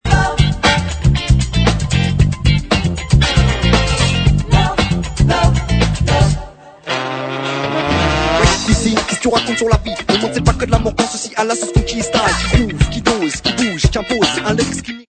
groove influences diverses